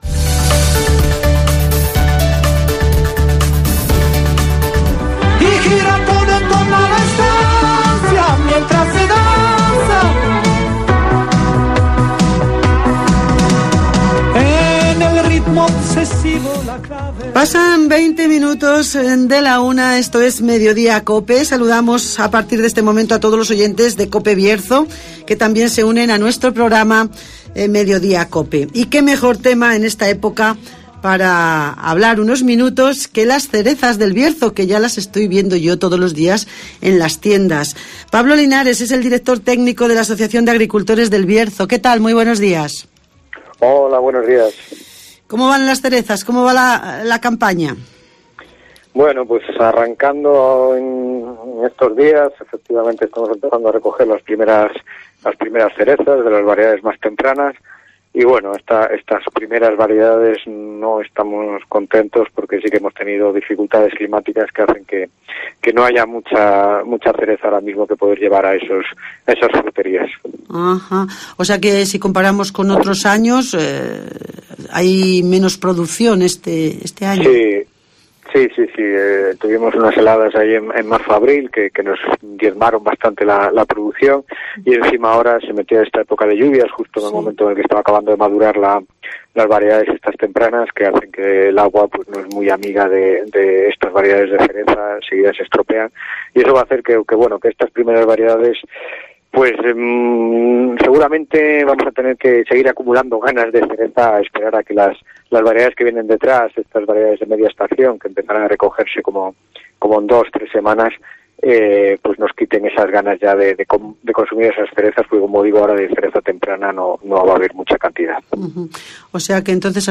Nos acercamos al campo berciano (Entrevista